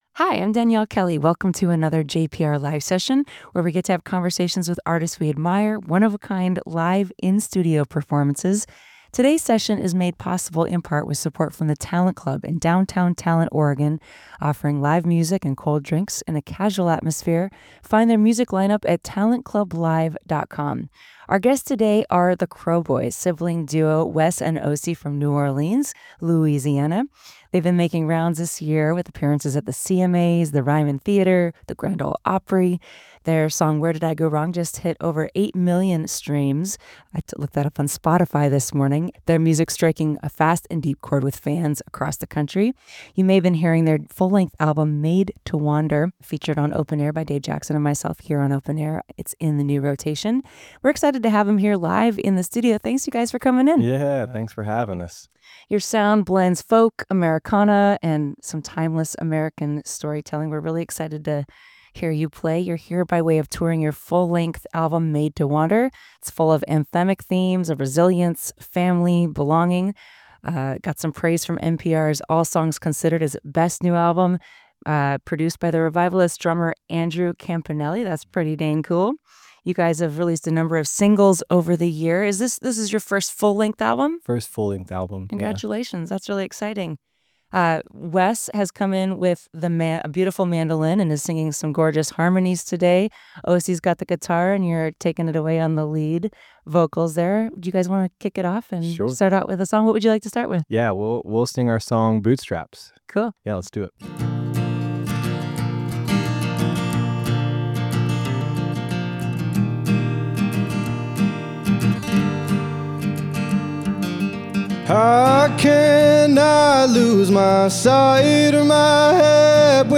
jpr-live-session-crowe-boys.mp3